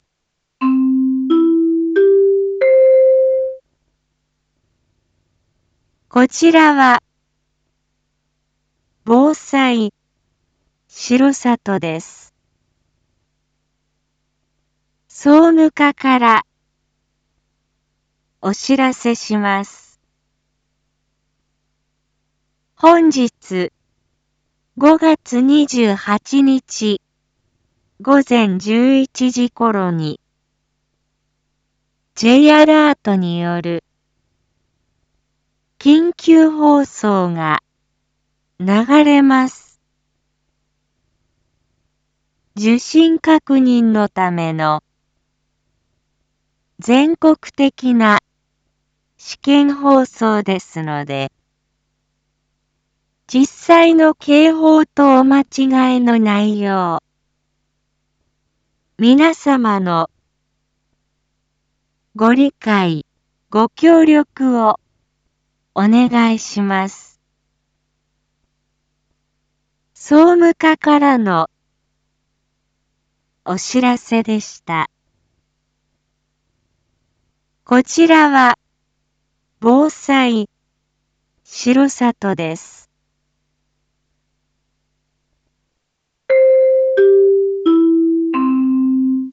一般放送情報
BO-SAI navi Back Home 一般放送情報 音声放送 再生 一般放送情報 登録日時：2025-05-28 07:01:31 タイトル：Jアラート（全国瞬時警報システム）訓練のための放送配信 インフォメーション：本日、５月２８日 午前１１時ころに、Ｊアラートによる緊急放送が流れます。